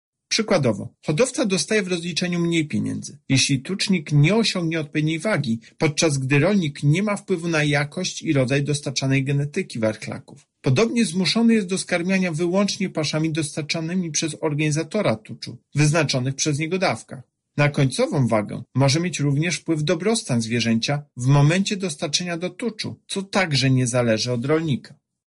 Uznałem, że obciążanie dostawców nadmiernym ryzykiem może stanowić nieuczciwe wykorzystanie przewagi kontraktowej przez duże firmy – mówi prezes UOKiK Tomasz Chróstny: